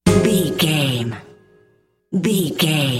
Aeolian/Minor
C#
percussion
flute
bass guitar
silly
circus
goofy
comical
cheerful
perky
Light hearted
quirky